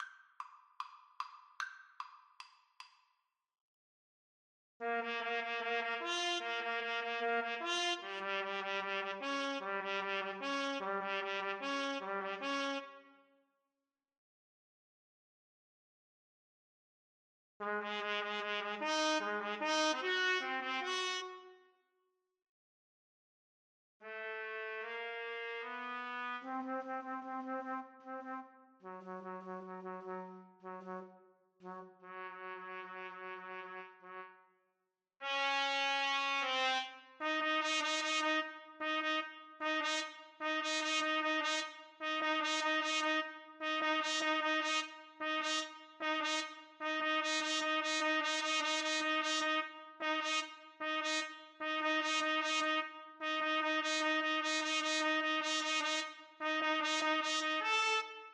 = 150 Allegro Moderato (View more music marked Allegro)
4/4 (View more 4/4 Music)
Classical (View more Classical Trumpet Duet Music)